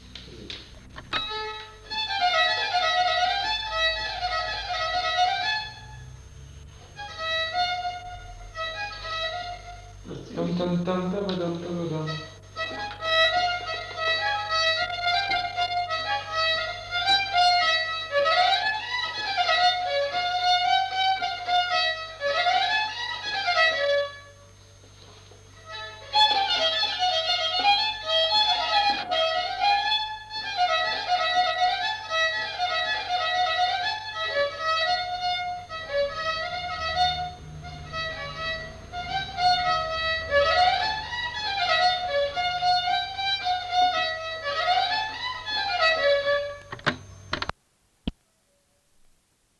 Lieu : Roquefort
Genre : morceau instrumental
Instrument de musique : violon
Danse : congo